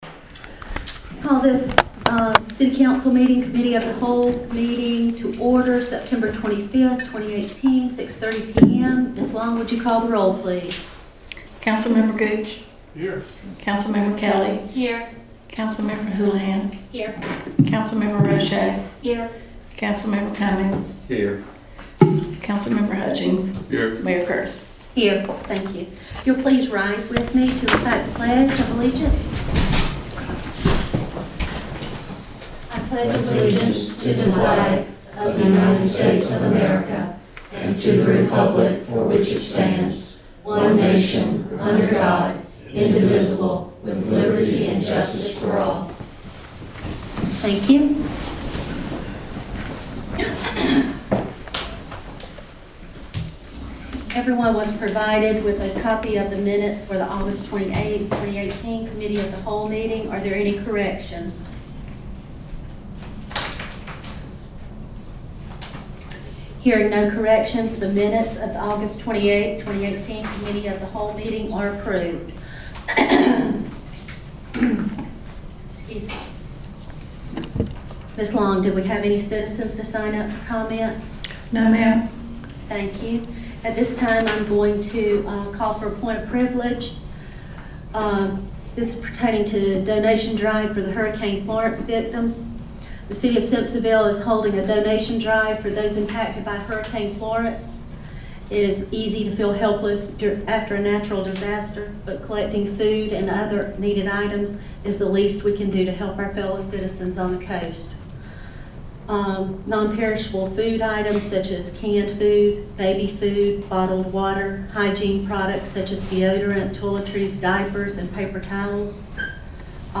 City Council Committee of the Whole Meeting